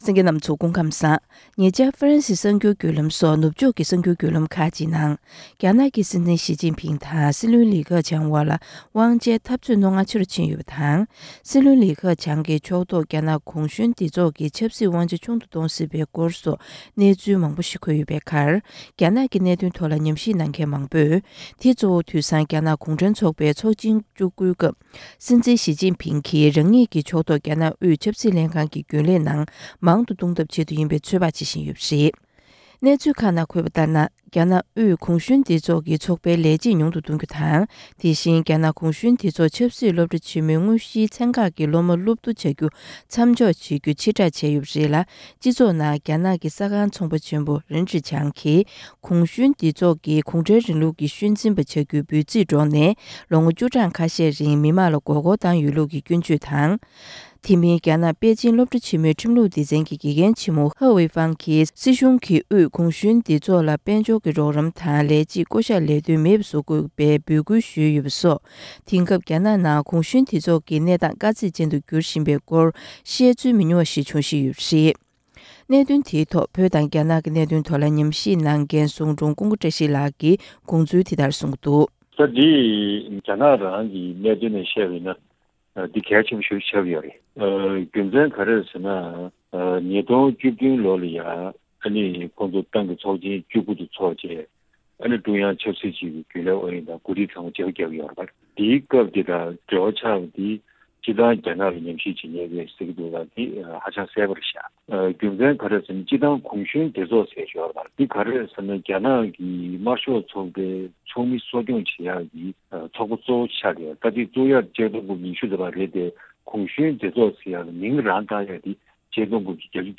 རྒྱ་ནག་དཔོན་རིགས་བར་དབང་ཆ་འཐབ་རྩོད་ཆེ་རུ་འགྲོ་བཞིན་པ། དུམ་བུ་དང་པོ། སྒྲ་ལྡན་གསར་འགྱུར།